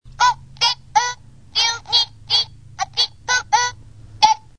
Bandai Haro interactive toy.